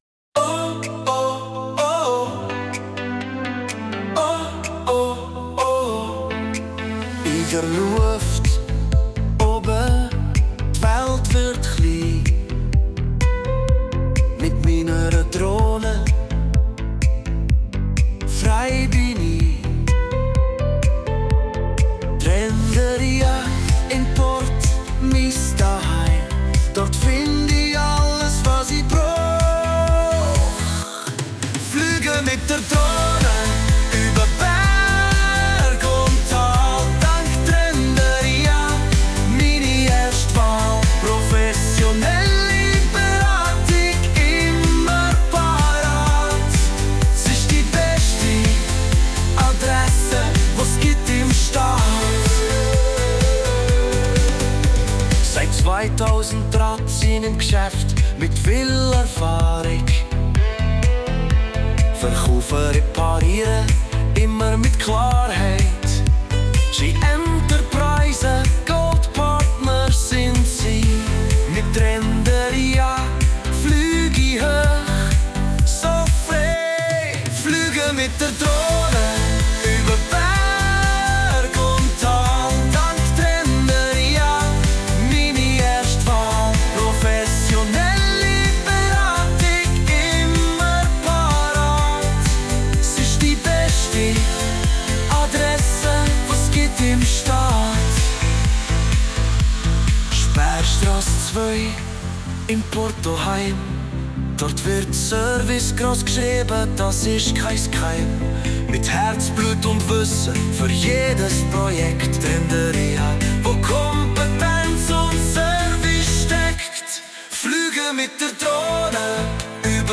Genres: Firmensong, Werbesong